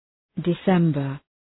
{dı’sembər}